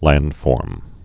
(lăndfôrm)